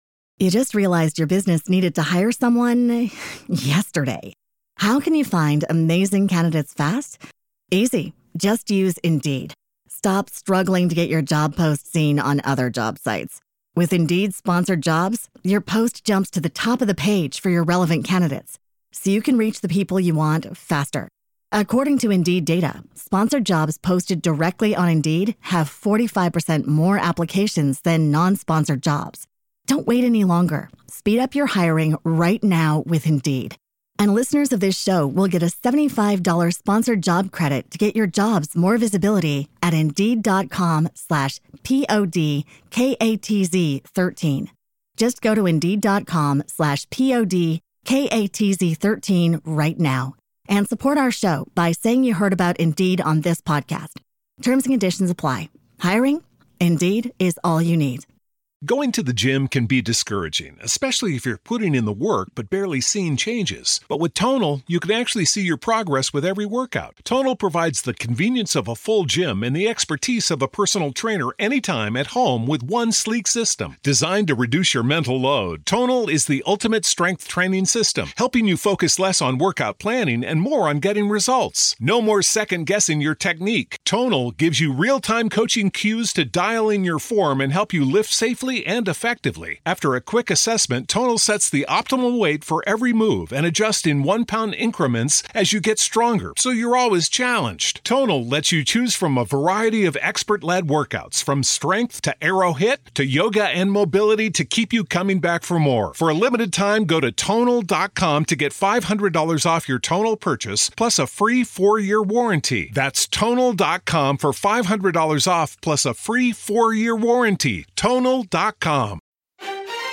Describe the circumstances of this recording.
She is here to debunk myths, clear up misconceptions and help you approach the subject that so many people are afraid to talk about, in a positive manner. Listen in for a new perspective.